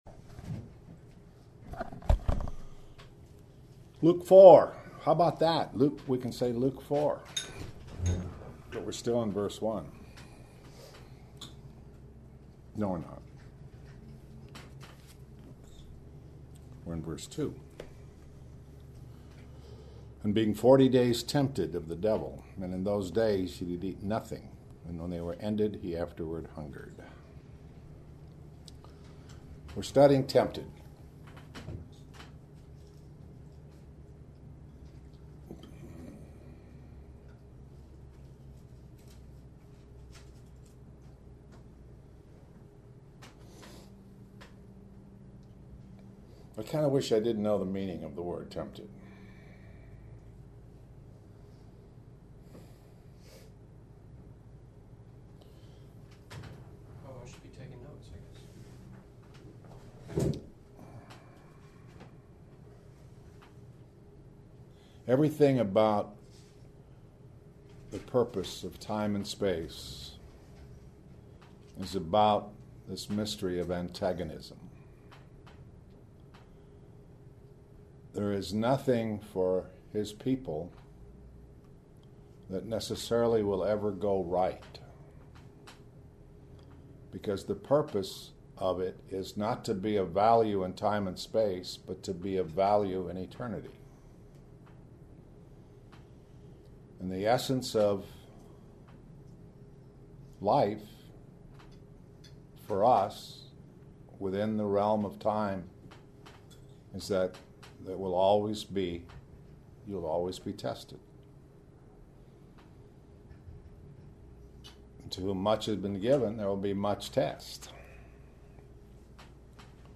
Morning Bible Studies